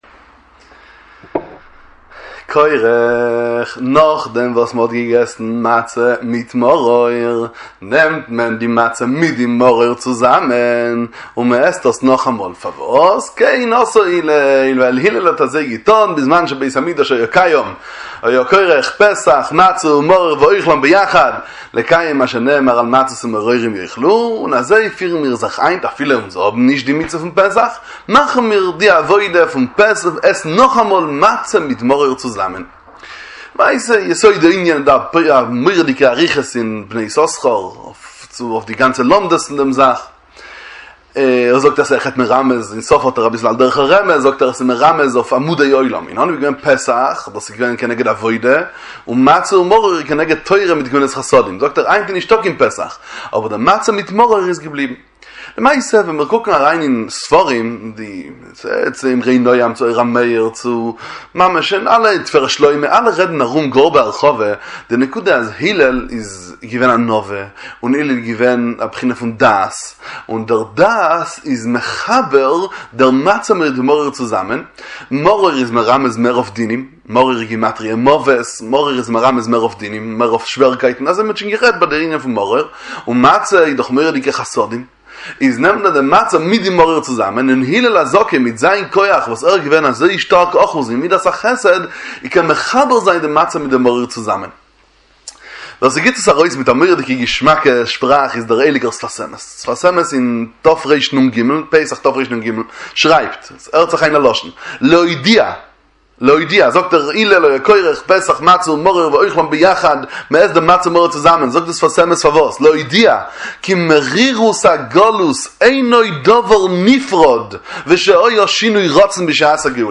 דבר תורה קצר לפסח וליל הסדר באידיש, הבנת סימני הסדר בתורת איזביצה, שיעורי תורה בפרשת השבוע ומועדי ישראל